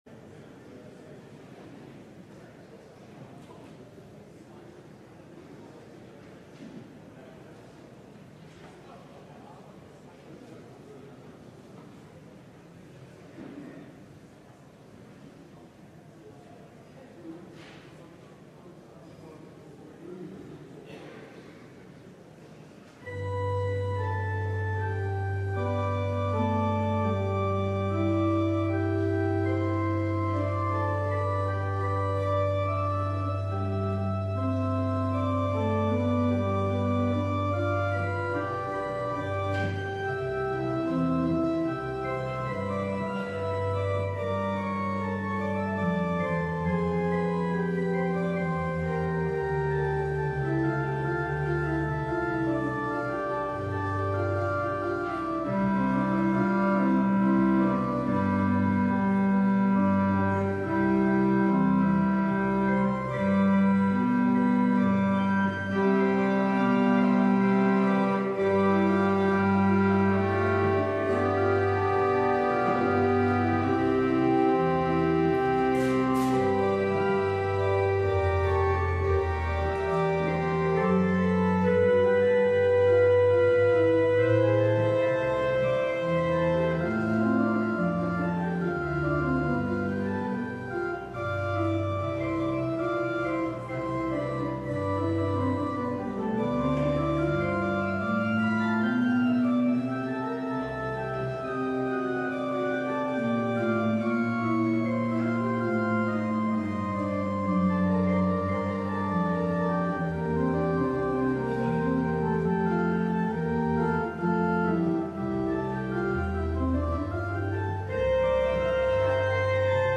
LIVE Morning Worship Service - The Care and Keeping of Covenants
Congregational singing—of both traditional hymns and newer ones—is typically supported by our pipe organ.